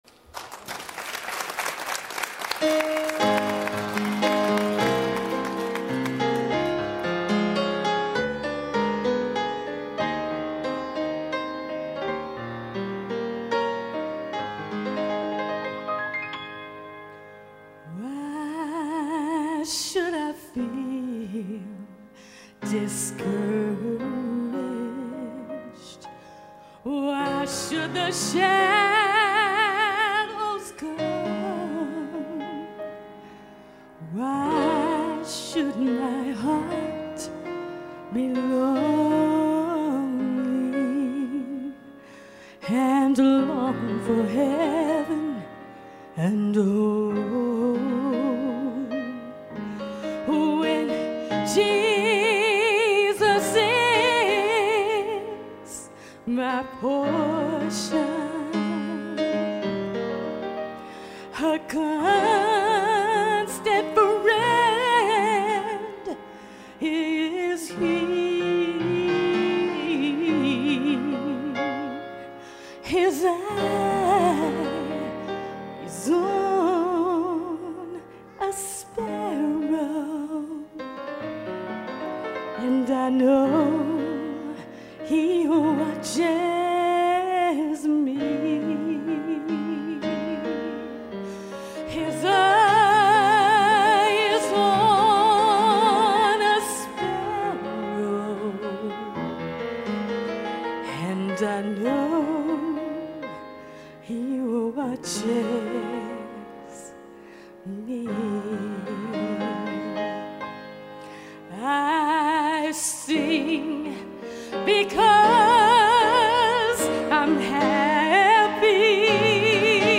Nashville Gospel Singer 1
This Nashville Gospel Singer is a dynamic and experienced worship leader.